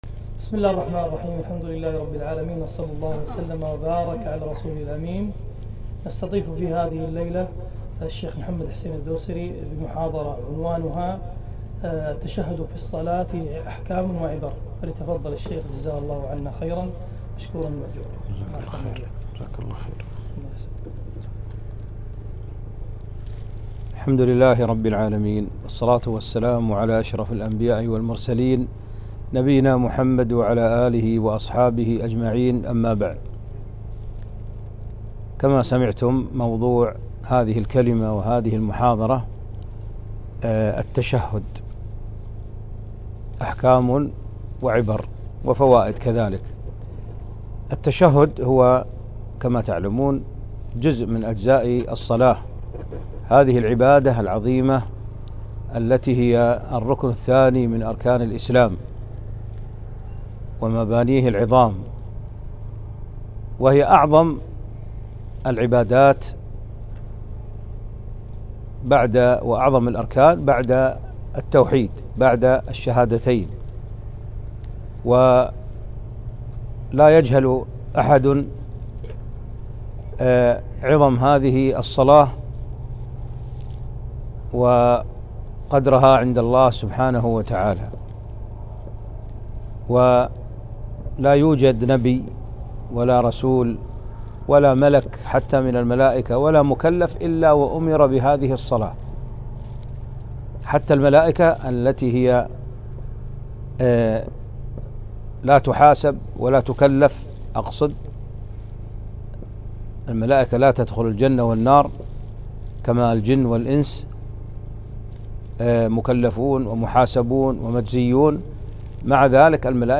محاضرة - التشهد في الصلاة أحكام وعبر - دروس الكويت